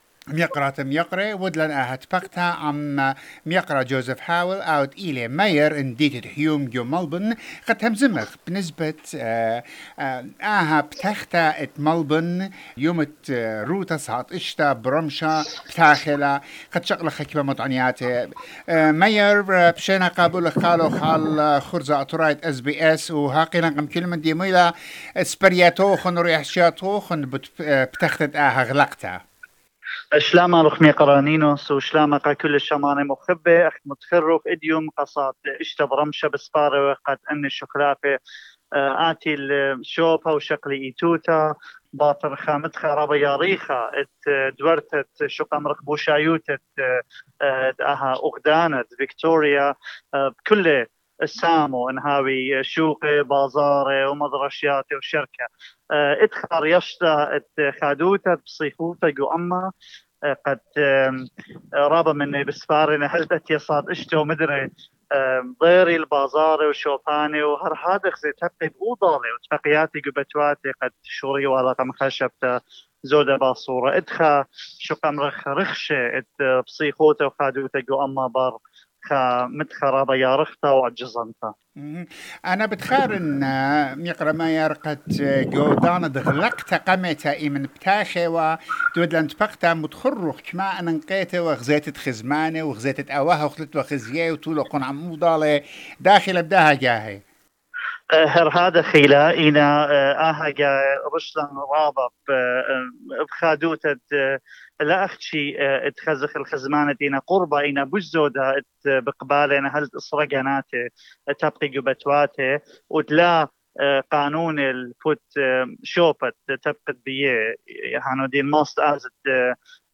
Mayor of Hume city council Councillor Joseph Haweil spoke to SBS about the opening and showed his joy as all Victorians for the new freedom that started from 6PM on Friday 29 October 2021. Mayor Haweil says his city few months ago was considered one of the lowest in vaccination rate, but now, big number of resident are vaccinated. Cr. Haweil talks about the latest storms that created so much damage to Hume and surrounding areas.